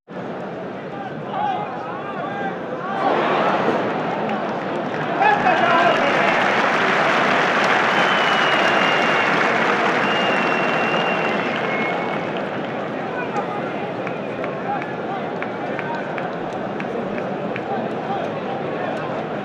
Aplausos del público en una plaza de toros con silbidos y uys